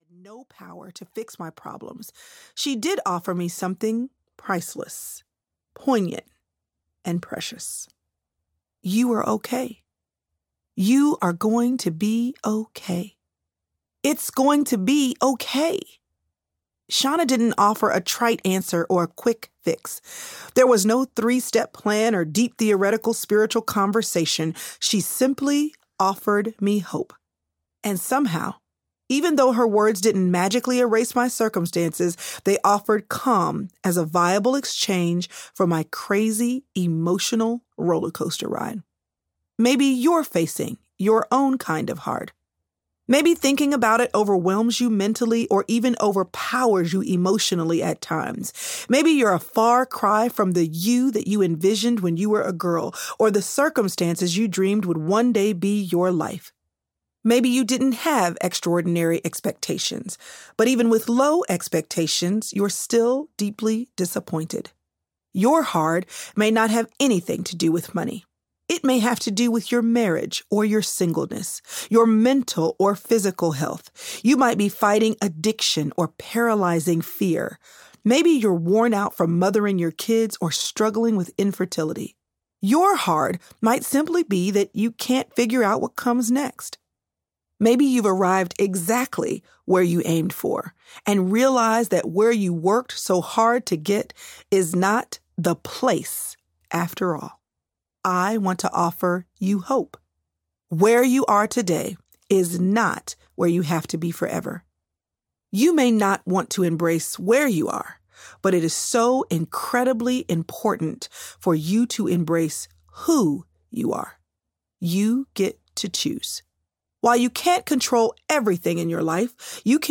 She’s Still There: Rescuing the Girl in You Audiobook